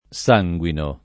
sanguinare
sanguinare v.; sanguino [ S#jgU ino ]